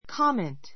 comment kɑ́ment カ メン ト 名詞 （短い） 論評, 批評, 意見, 解説, コメント make a comment make a comment 意見を述べる, コメントする write a comment on ～ write a comment on ～ ～について短い論評を書く No comment.